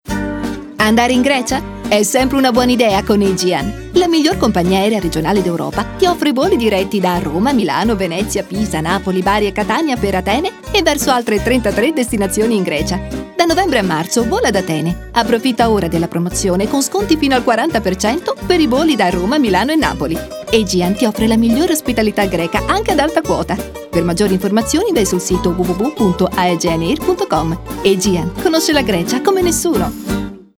Also the radio spot he recently played in Italy, which smells Greece!